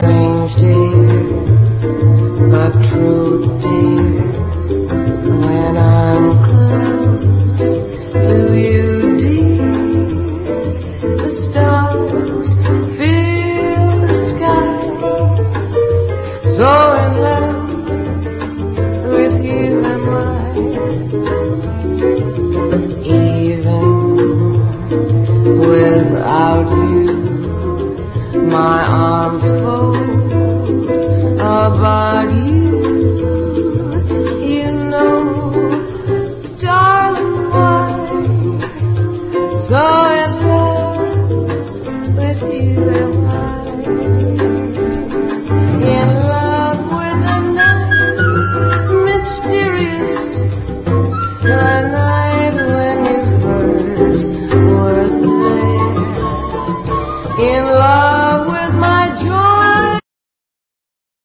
JAZZ / JAZZ VOCAL